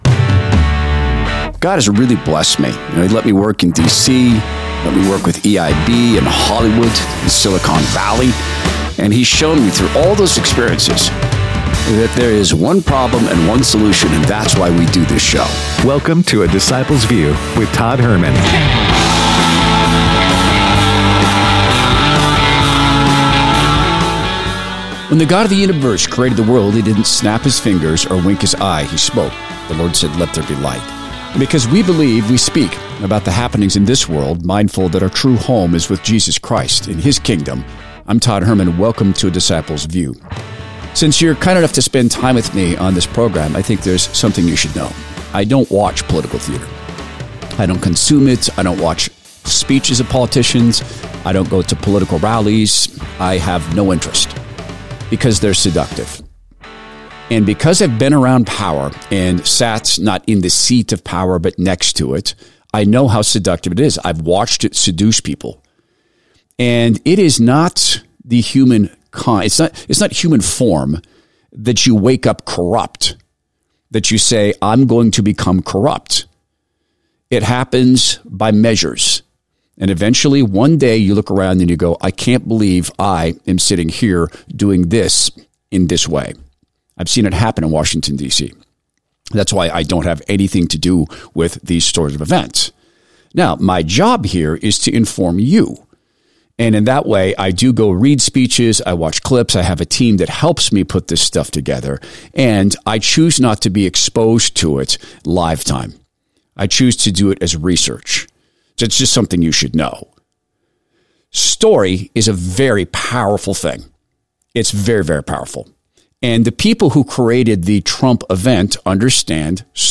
Former Trump Adviser Peter Navarro Speaks At The RNC
Show Notes He addresses the RNC just hours after being released from prison.